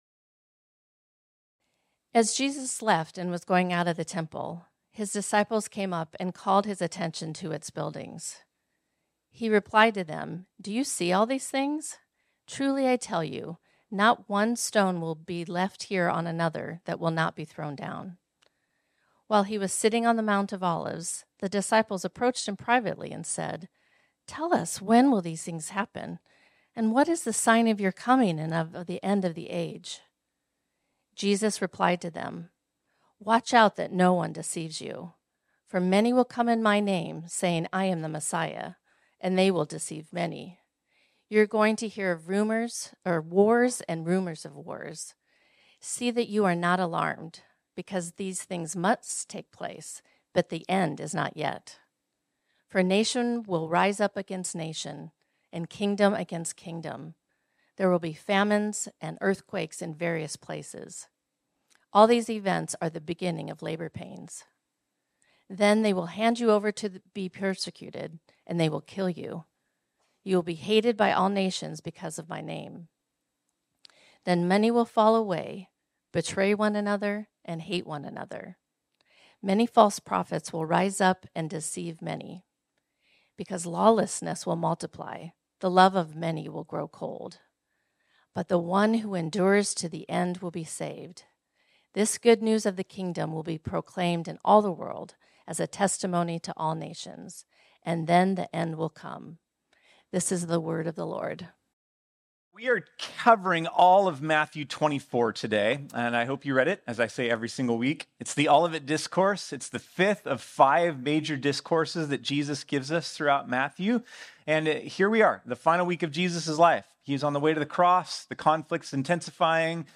This sermon was originally preached on Sunday, October 20, 2024.